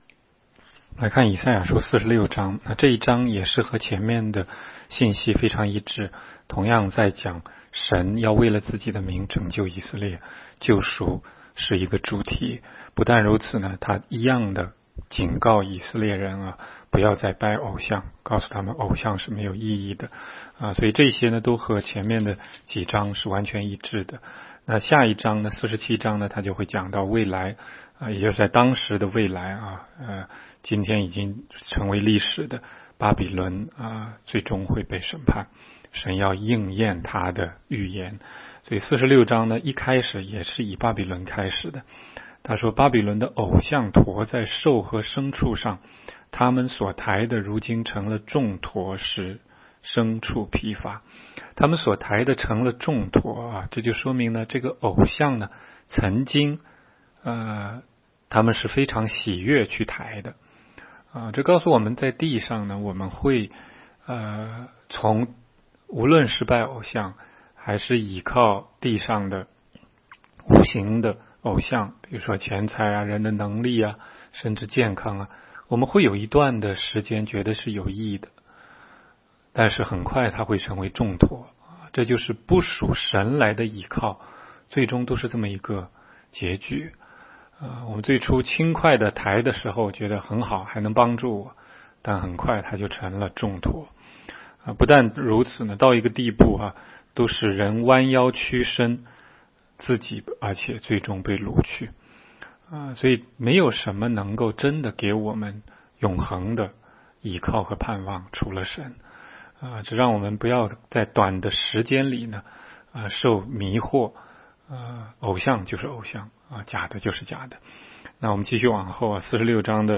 16街讲道录音 - 每日读经 -《以赛亚书》46章